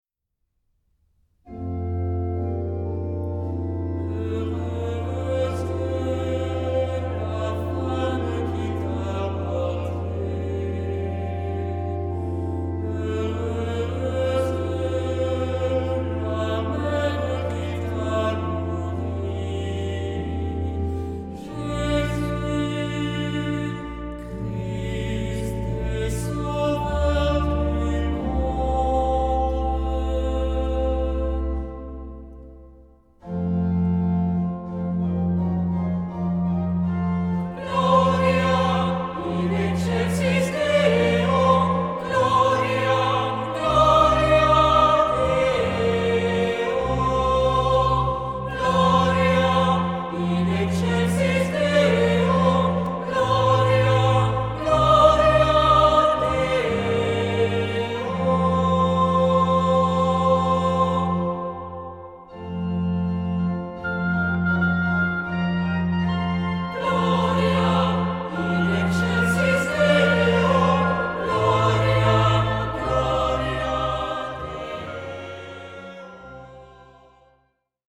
Genre-Style-Forme : Tropaire ; Psalmodie
Caractère de la pièce : recueilli
Type de choeur : SAH OU SATB  (4 voix mixtes )
Instruments : Orgue (1) ; Instrument mélodique (ad lib)
Tonalité : fa majeur